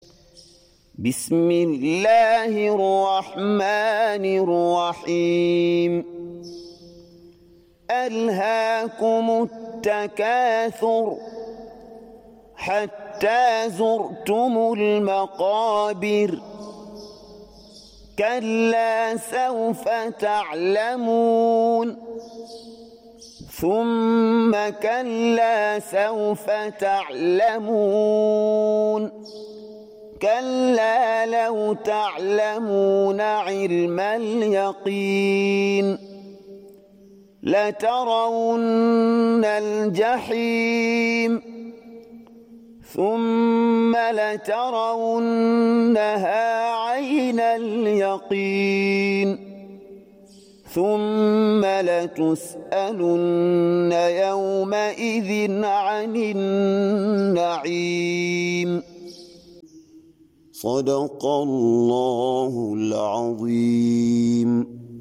102. Surah At-Tak�thur سورة التكاثر Audio Quran Tarteel Recitation
Surah Sequence تتابع السورة Download Surah حمّل السورة Reciting Murattalah Audio for 102.